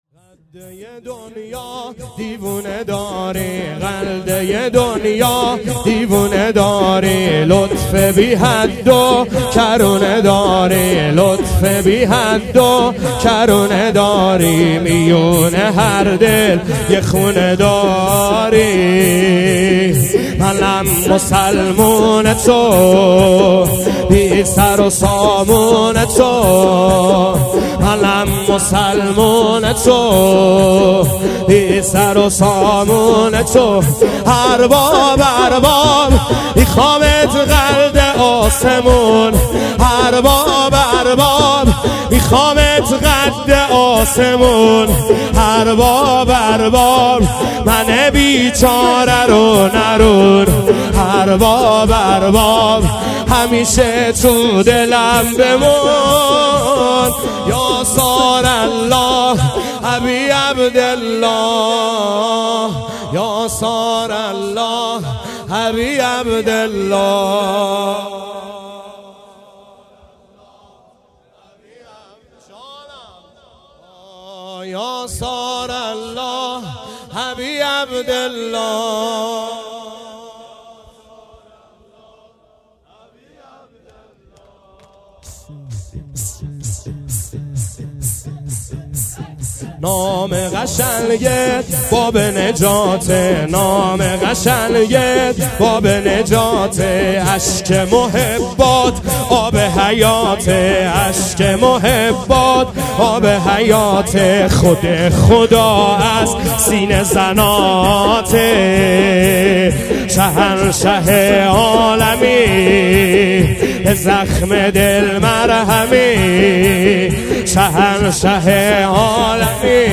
شور | قد یه دنیا دیوونه داری
گزارش صوتی شب دوم محرم 98 | هیأت محبان حضرت زهرا سلام الله علیها زاهدان